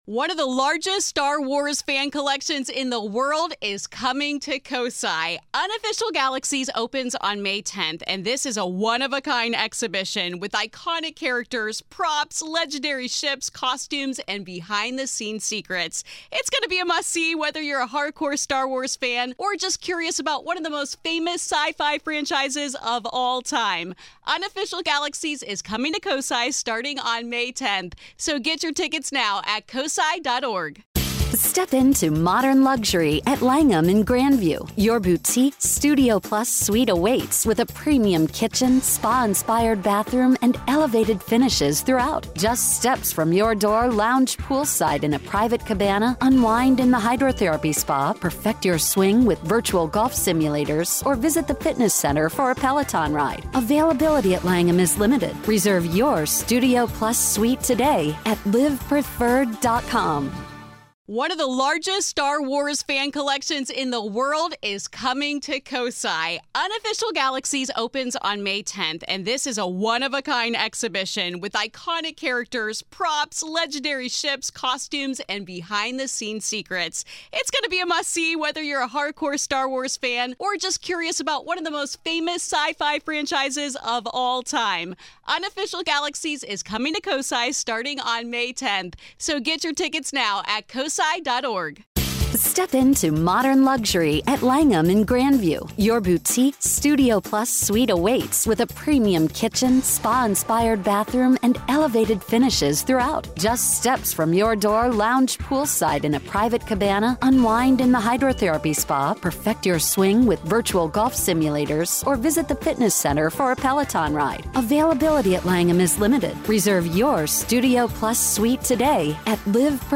Roundtable; 2024, UFOs: Whats to come... (Pt.2)